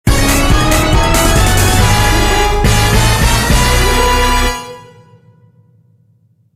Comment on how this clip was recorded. Ripped from the ISO This is a sample from a copyrighted musical recording.